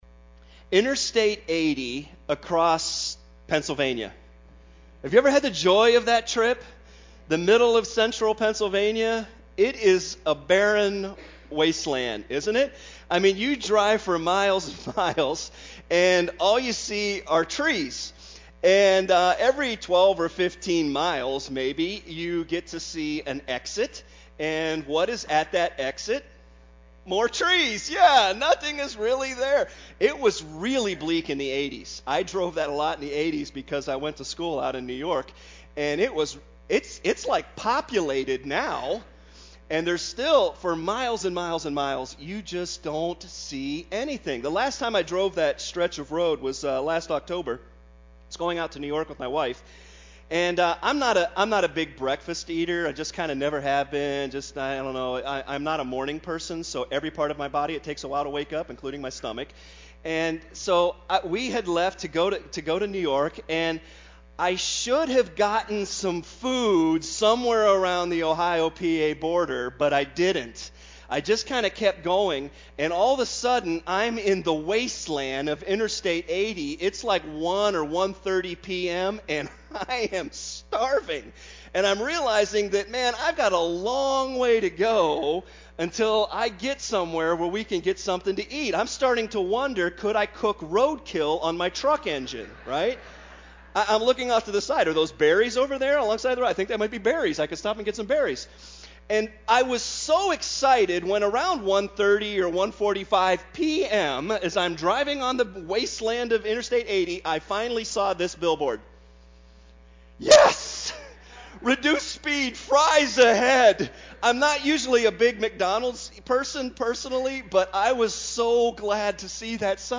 Summer Sermon Series 2018